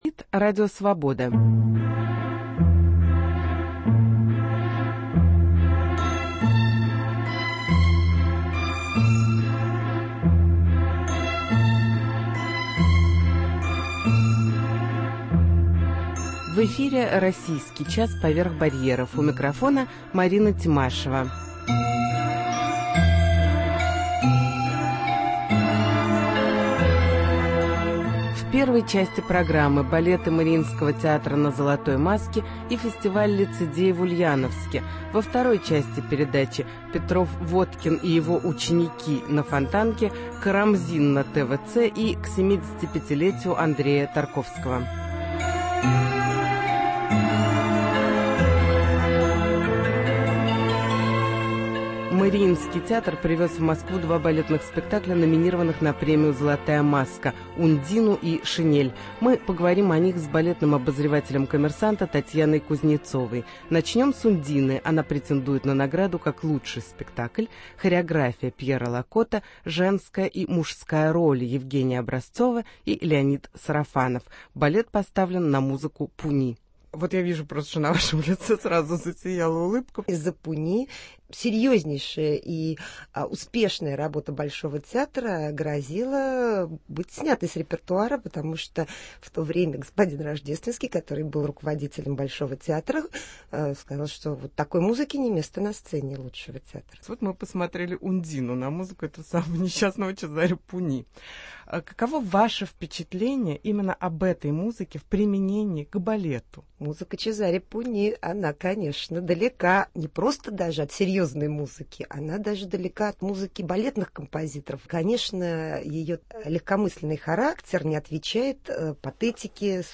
К 75-летию Андрея Тарковского - разговор с Маргаритой Тереховой Балеты Мариинского театра на фестивале "Золотая Маска" Кузьма Петров-Водкин и его ученики в галерее на Фонтанке Карамзин и Шевчук на ТВЦ Фестиваль "Лицедей" в Ульяновске